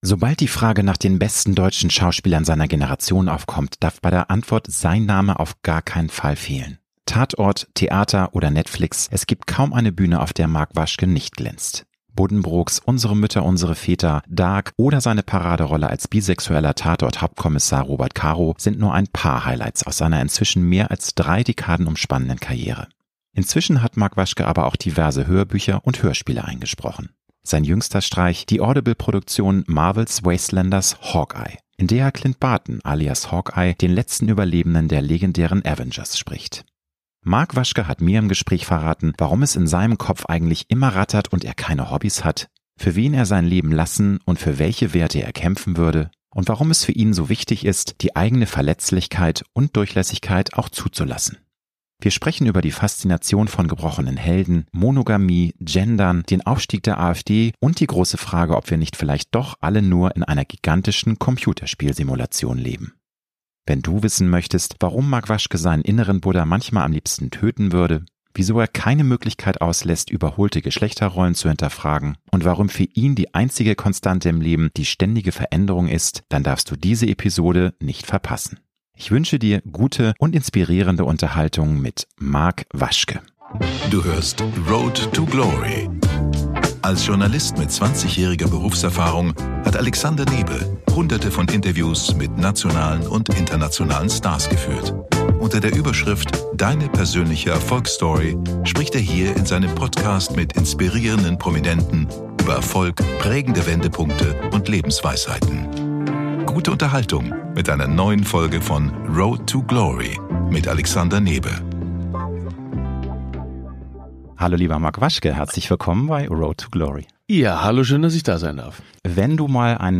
Mark Waschke hat mir im Gespräch verraten, warum es in seinem Kopf eigentlich immer rattert und er keine Hobbys hat, für wen er sein Leben lassen und wer welche Werte er kämpfen würde und warum es für ihn so wichtig ist, die eigene Verletzlichkeit und Durchlässigkeit auch zuzulassen. Wir sprechen über die Faszination von gebrochenen Helden, Monogamie, Gendern, den Aufstieg der AfD und die große Frage, ob wir nicht vielleicht doch alle nur in einer gigantischen Computerspiel-Simulation leben.